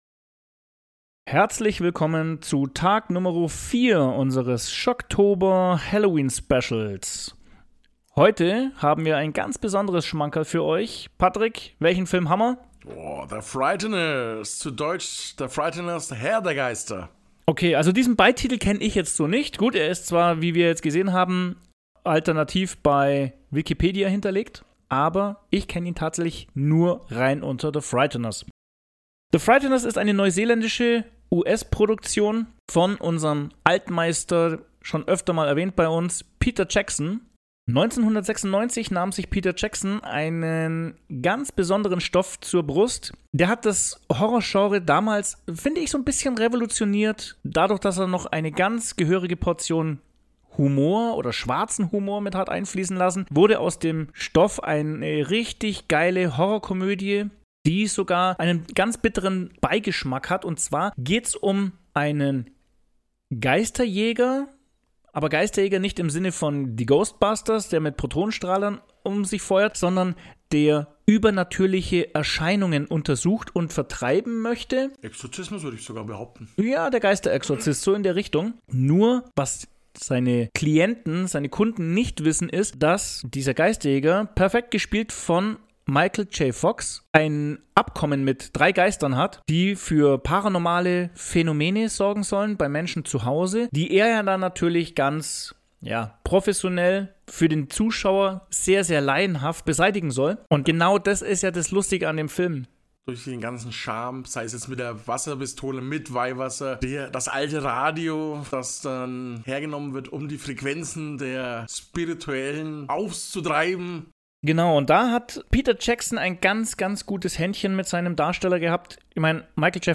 Im vierten Teil des Halloween-Specials analysieren und bewerten die beiden Podcaster Peter Jacksons Frühwerk und erzählen aus persönlicher Sicht, wie sie den Film erlebt haben.